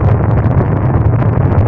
FLAMETHROWER
1 channel
FLAMETHR.mp3